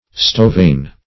stovain - definition of stovain - synonyms, pronunciation, spelling from Free Dictionary
Stovain \Sto"va*in\, n. Also Stovine \Sto"vine\ .